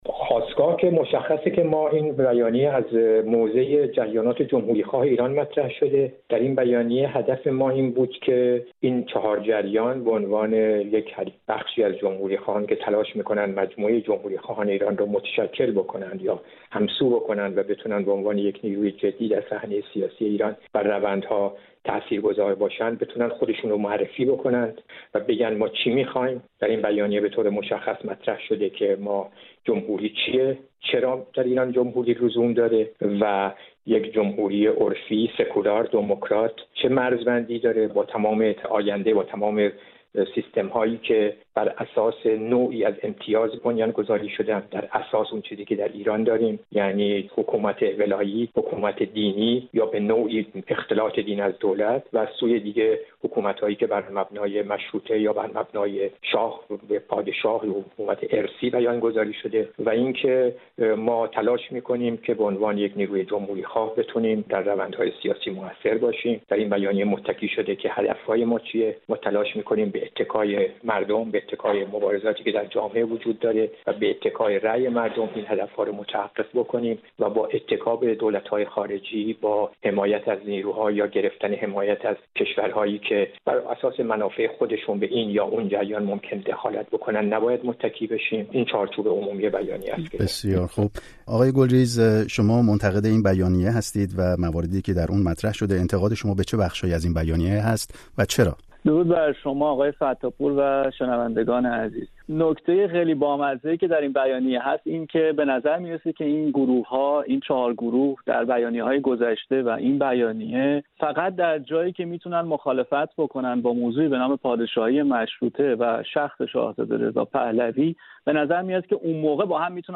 مناظره درباره آینده سیاسی ایران: جمهوری عرفی یا سلطنت مشروطه؟